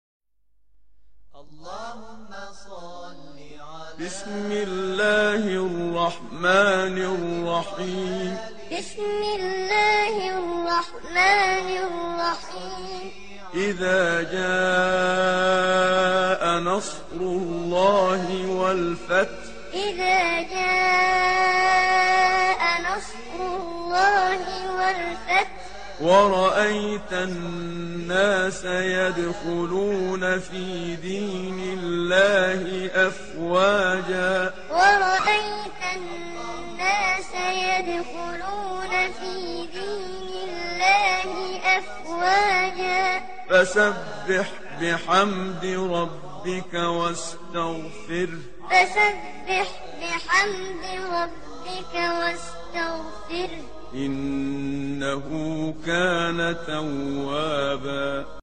شعر سوره نصر کودکانه صوتی